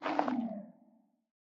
sculk_clicking_stop2.ogg